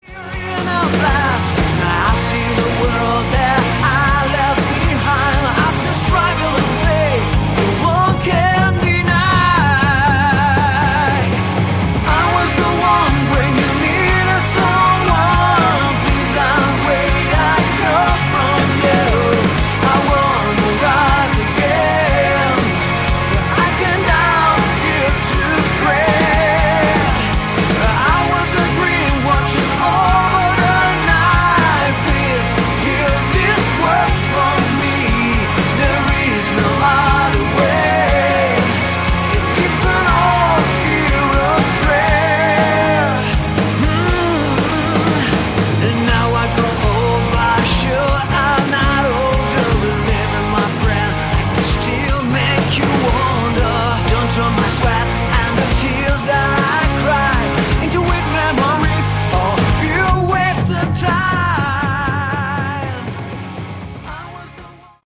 Category: Melodic Hard Rock
lead and backing vocals
rhythm guitars, lead and acoustic guitars
lead guitars, guitar solos
bass
drums